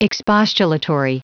Prononciation du mot expostulatory en anglais (fichier audio)
Prononciation du mot : expostulatory